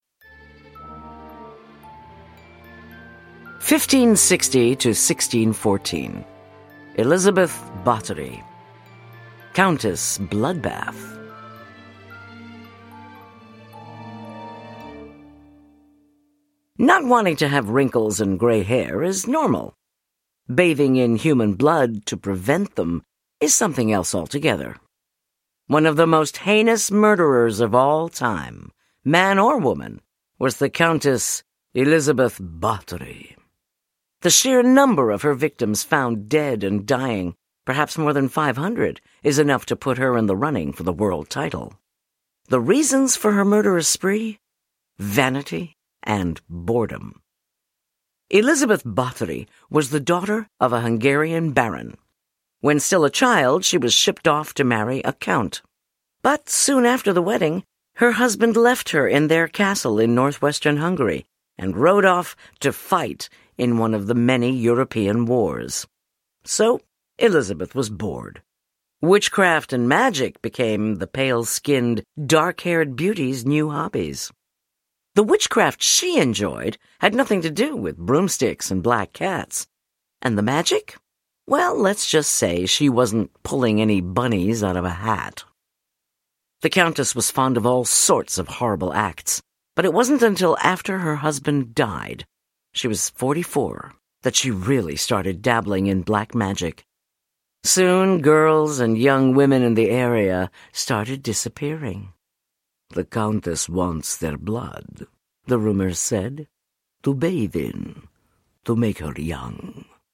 Middle Grade Audiobooks